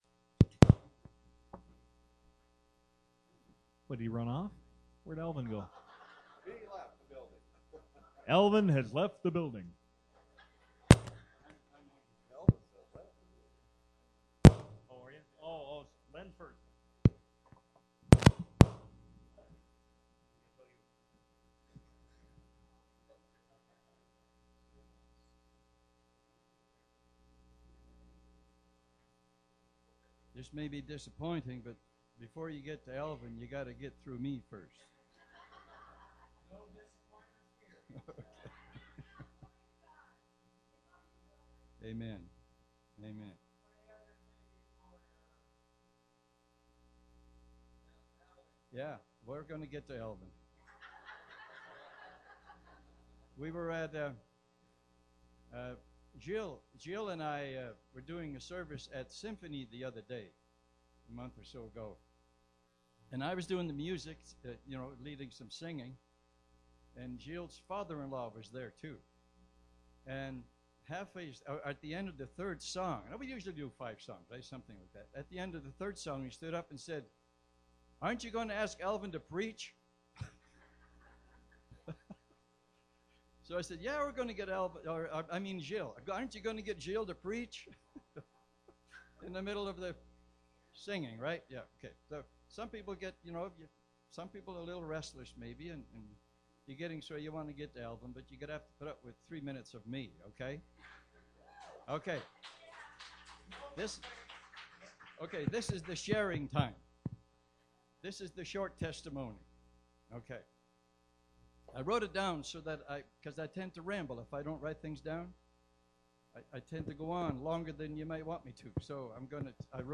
Sunday July 28th Sermon Audio | Potters Hands Ministries
Click This Link To Hear the July 28th Sunday Sermon